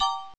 DreChron Piano Hit Synth.wav